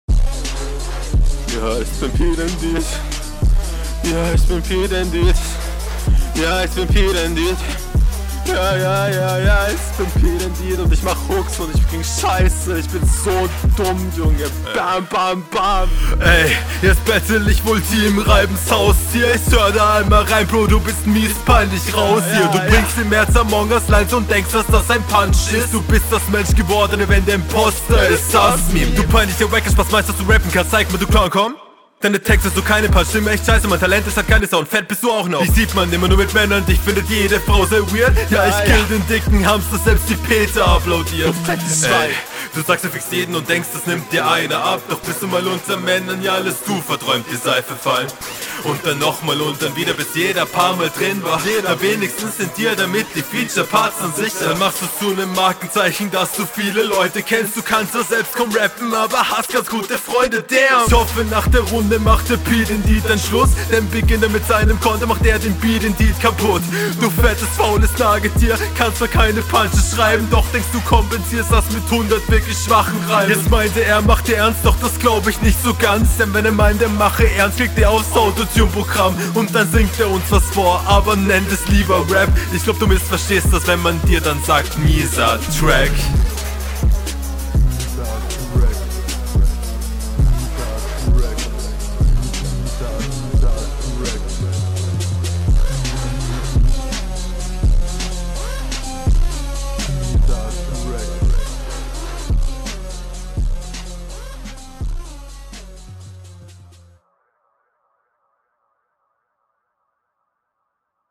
Der Doubletime ist offbeat.